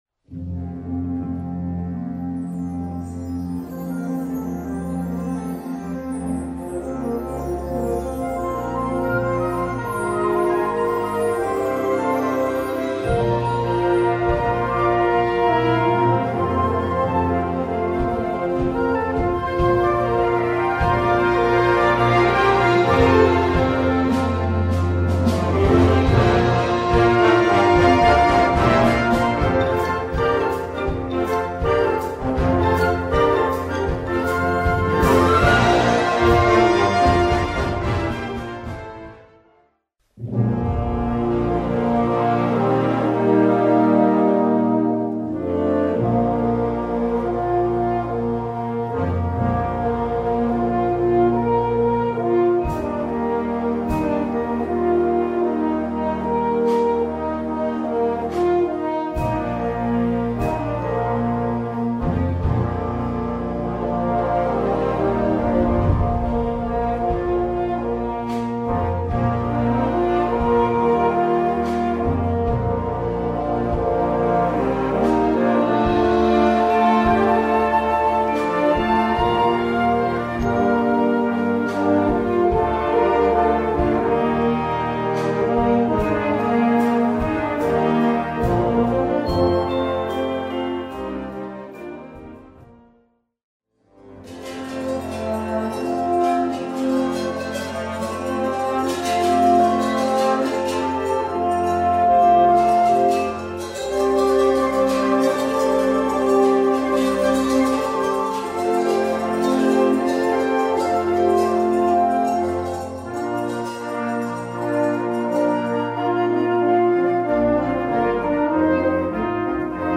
Gattung: Konzertwerk
Besetzung: Blasorchester
Alles wird schneller, kraftvoller, ungestümer.